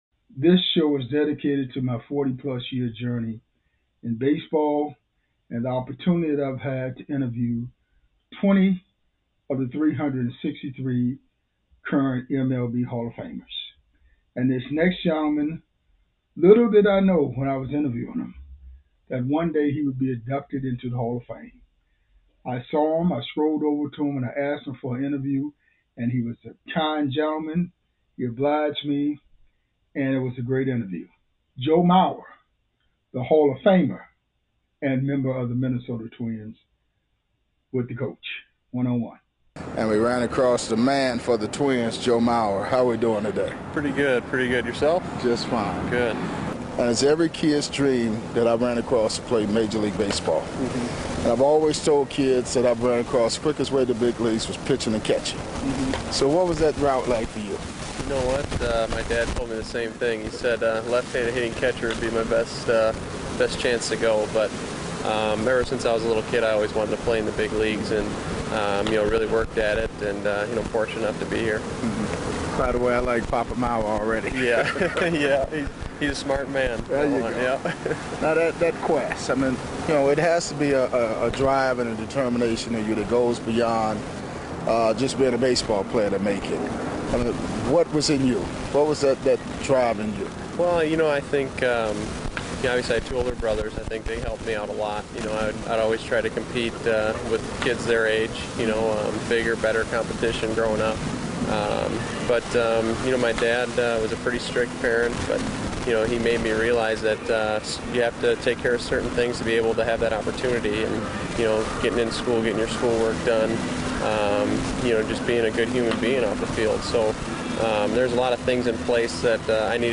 MLB Classic Interviews